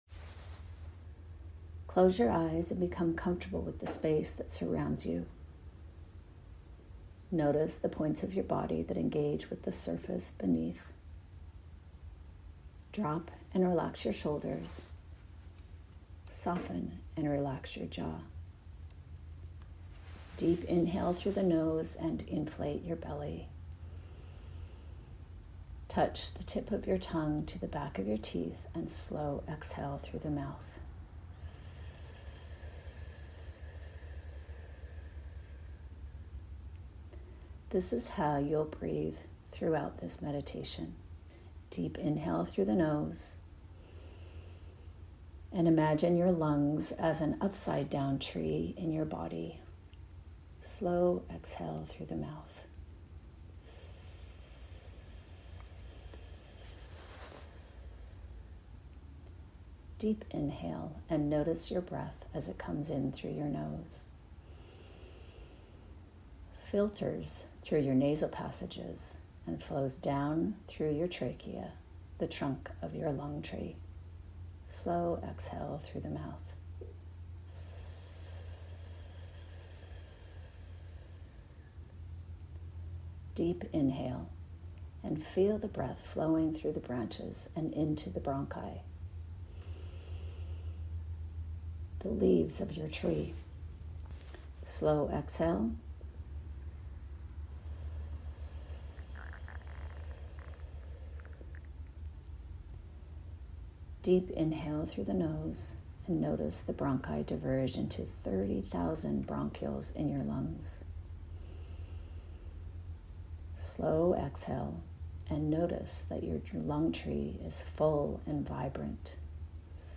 Guided meditation for you to love your lungs.